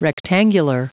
Prononciation du mot rectangular en anglais (fichier audio)
Prononciation du mot : rectangular